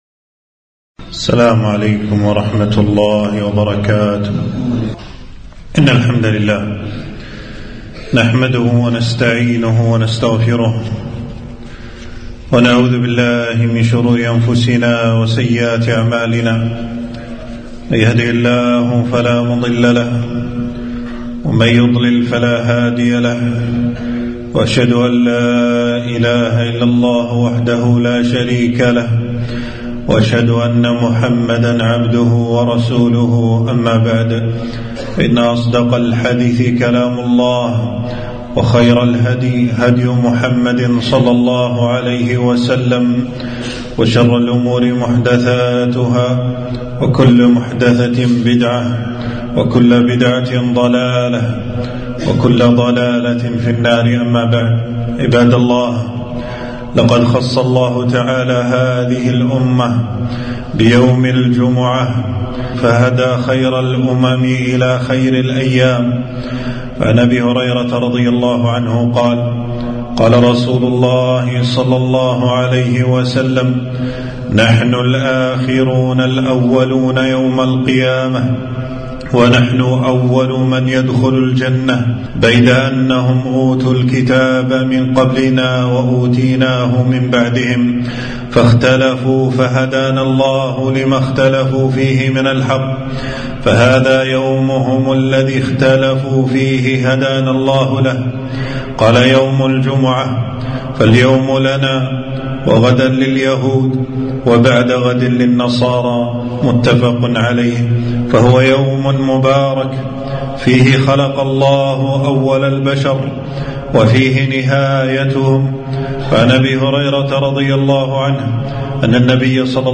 خطبة - الجمعة أحكام وآداب